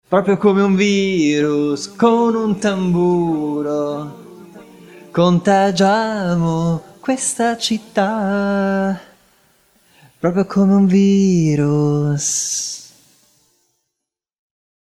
In post-production, I used a odd tempo delay (3/2) on the voice occasionally, panned hard left-right, to give space and echo. In this sample you hear the Sonar automation in action where it is there and then it’s not there:
virus-voxdelay3-2.mp3